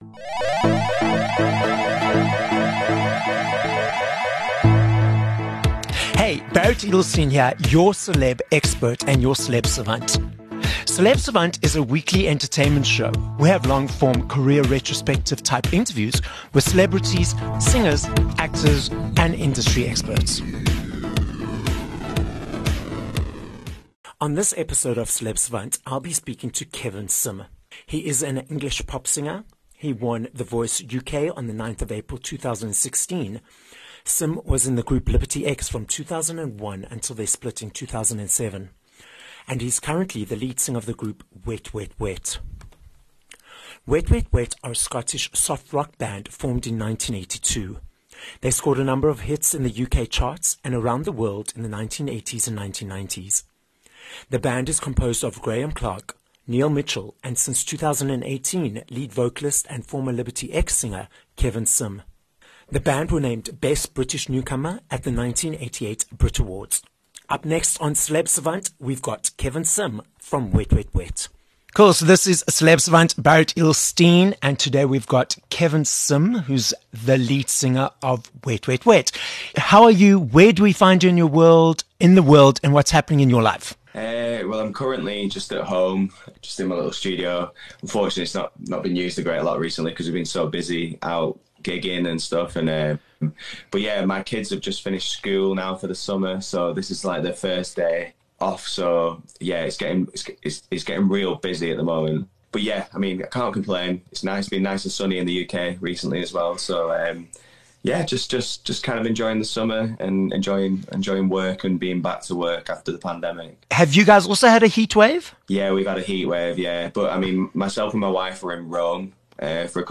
23 Aug Interview with Kevin Simm from Wet Wet Wet